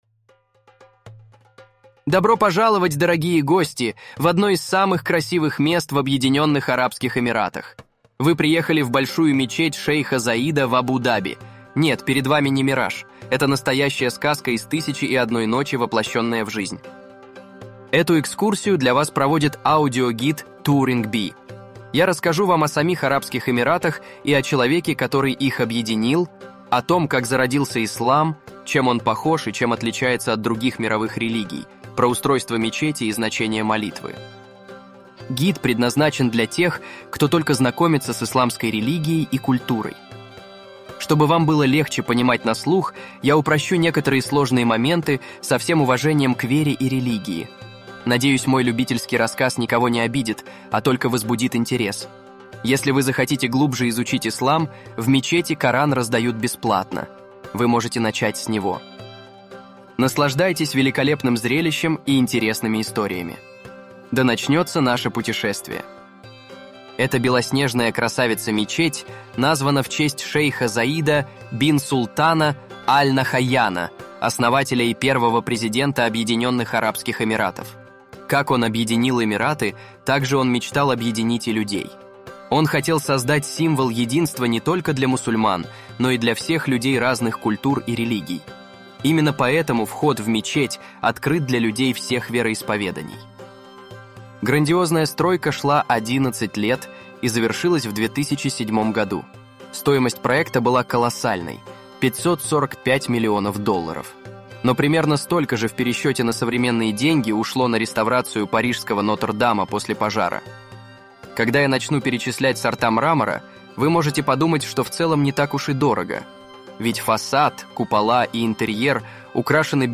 Абу-Даби: аудиогид по мечети шейха Зайда на русском от TouringBee